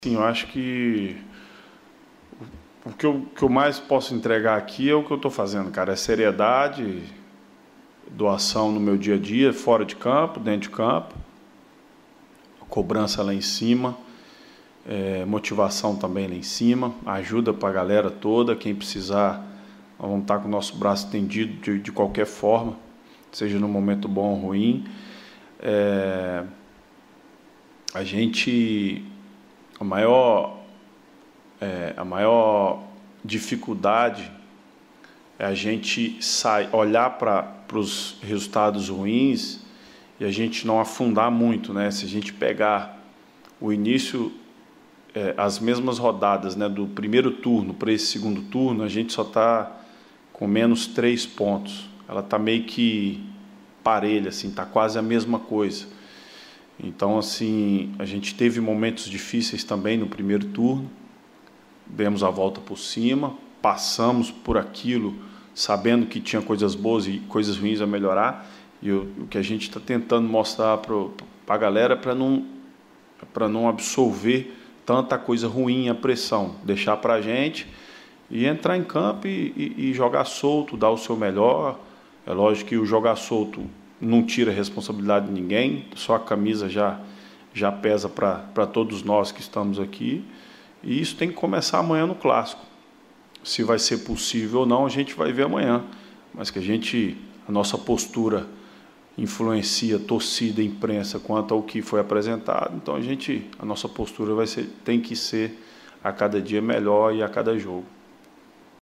O atacante participou da videoconferência desta terça-feira (05.01) com os jornalistas e falou sobre este tema e outros assuntos importantes.